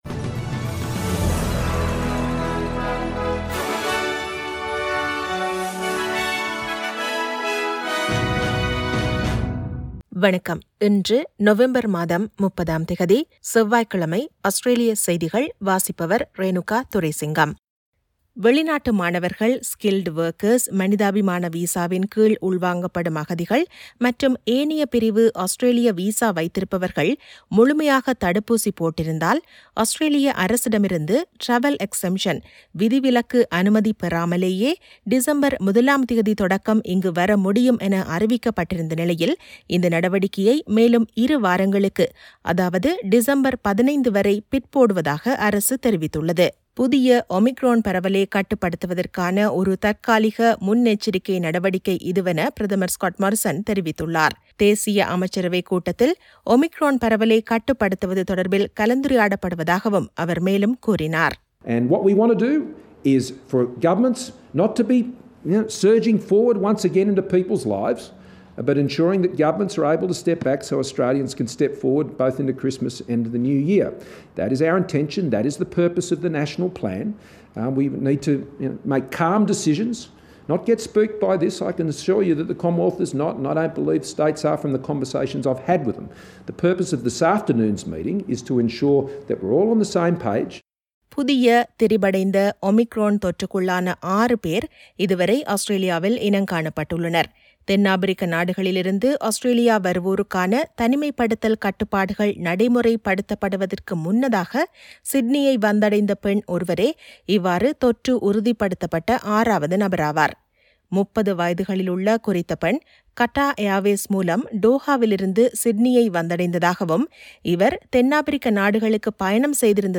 Australian news bulletin for Tuesday 30 Nov 2021.